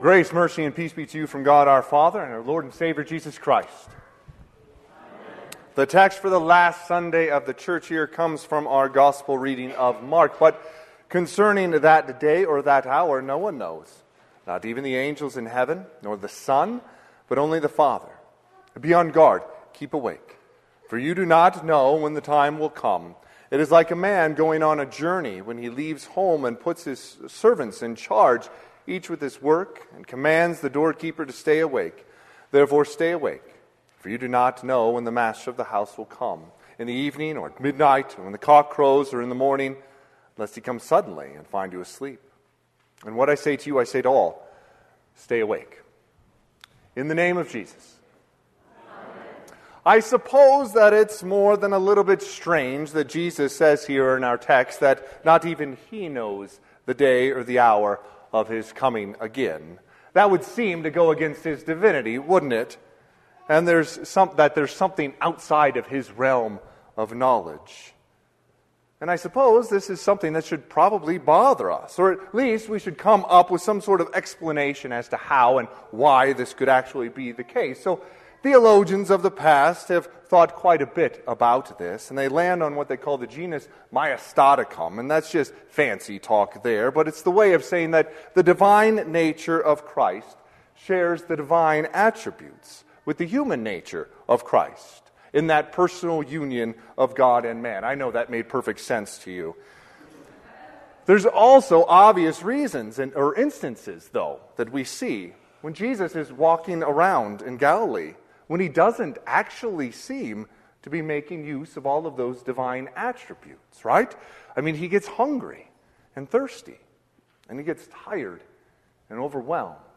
Sermon - 11/24/2024 - Wheat Ridge Lutheran Church, Wheat Ridge, Colorado
Last Sunday of the Church Year